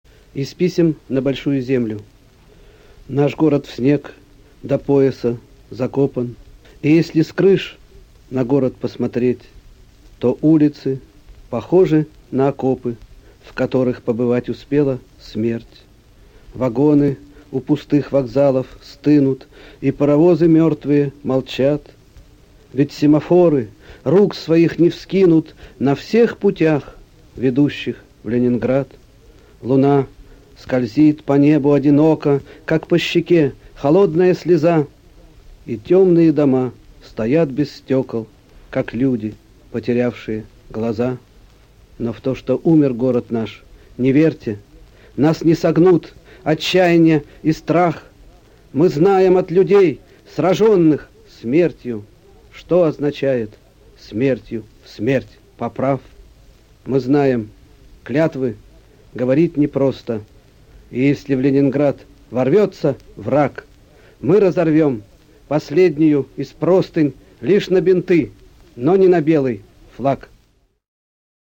Юрий Воронов – Из писем на Большую Землю… (читает автор)
yurij-voronov-iz-pisem-na-bolshuyu-zemlyu-chitaet-avtor